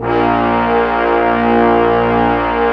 55m-orc04-A#1.wav